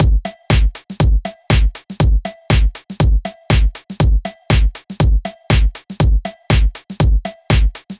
29 Drumloop.wav